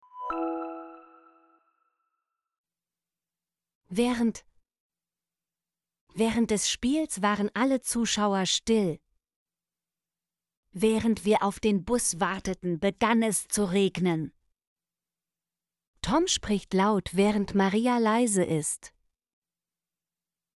während - Example Sentences & Pronunciation, German Frequency List